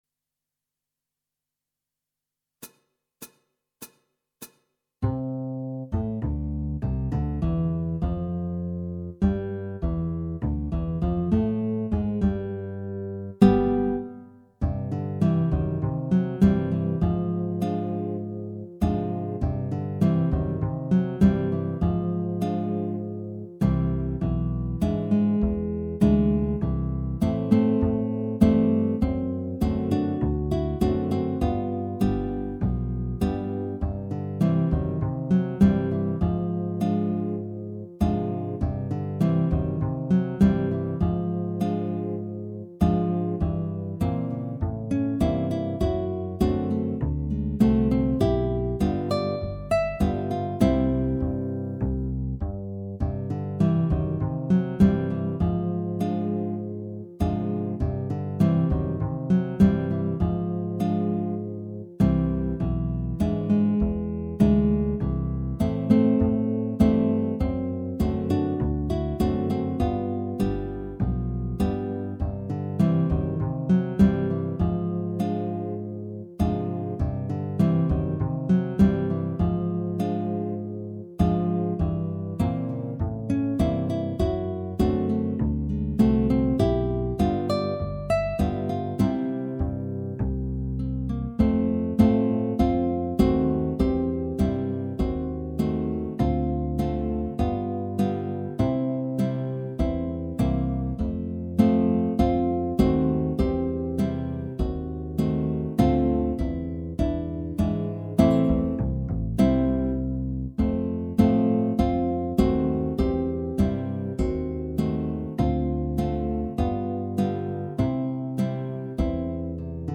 Guitar Quartet
minus Guitar 3